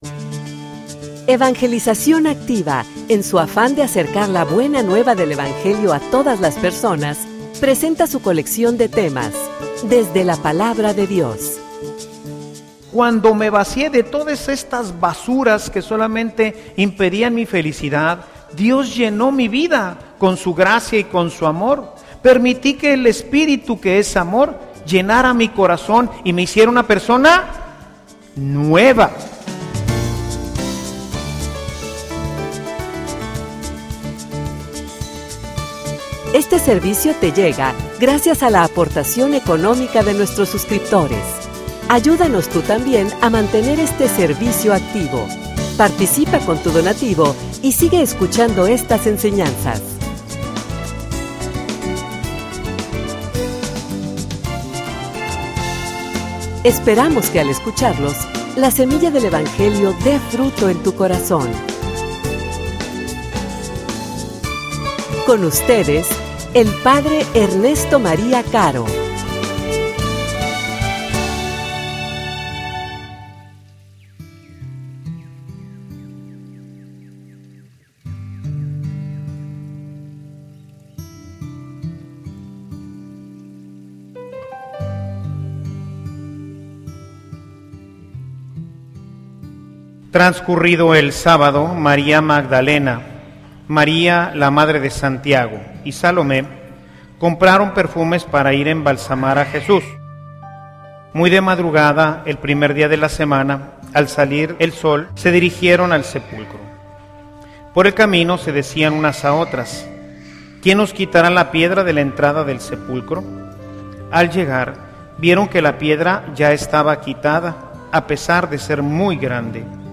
homilia_El_hombre_nuevo.mp3